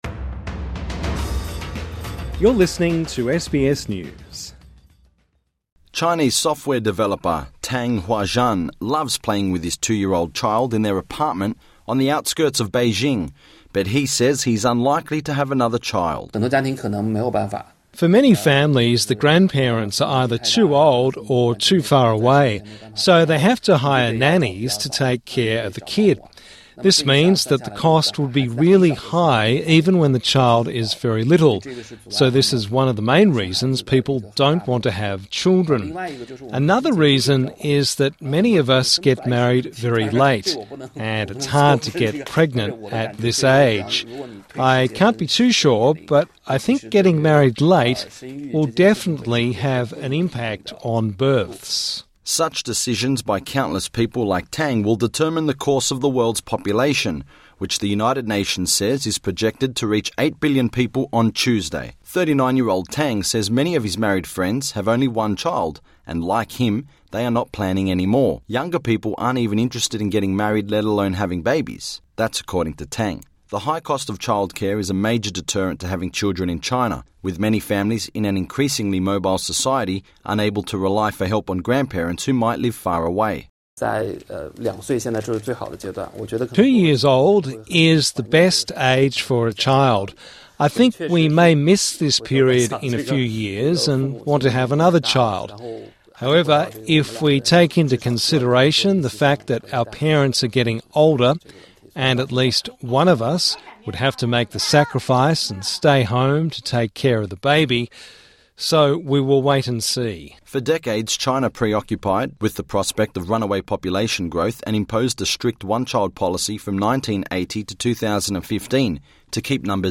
But in China, population growth is slowing, with a declining birth rate and rapidly aging population. SBS News profiles a three generation family in China, who discuss why enthusiasm for having children is decreasing; and an expert who highlights the broader demographic challenges, for the country.